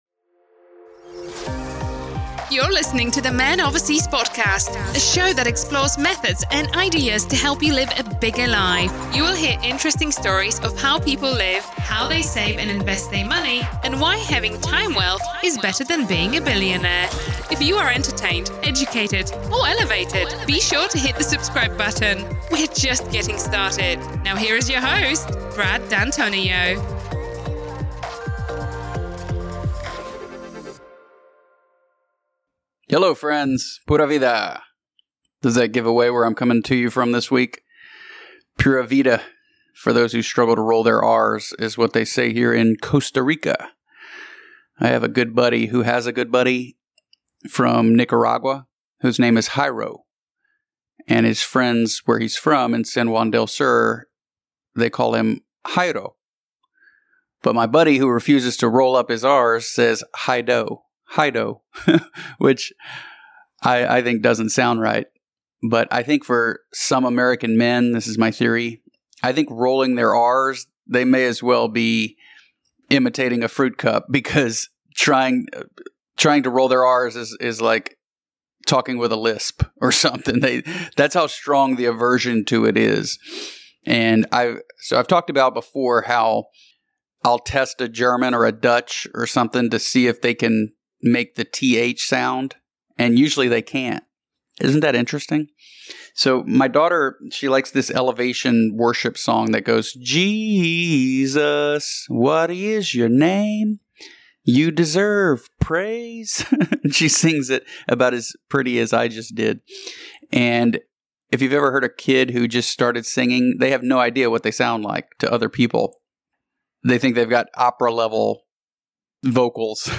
Coming to you solo from Costa Rica this week. In this episode, I unpack the growing conversation around Christian nationalism—how the media has weaponized the term, what it reveals about global propaganda, and why nationalism itself has been wrongly vilified.